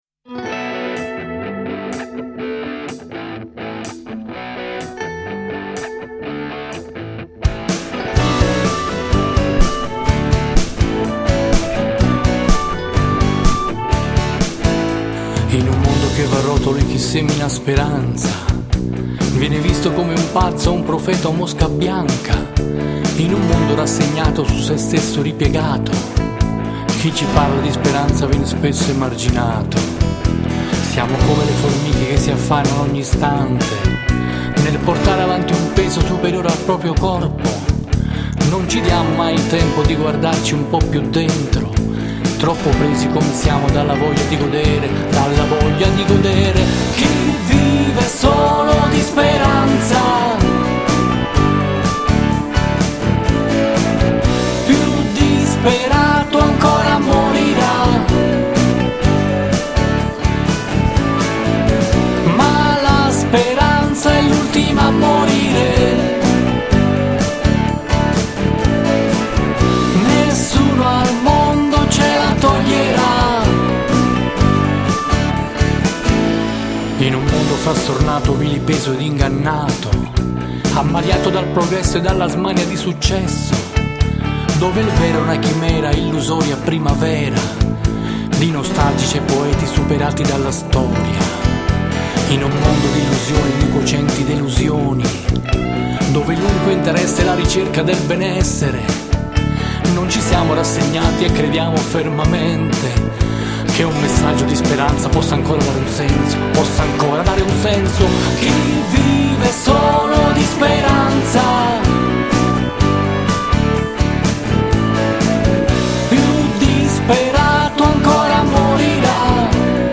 Il brano con l’impronta più rock dell’intero progetto!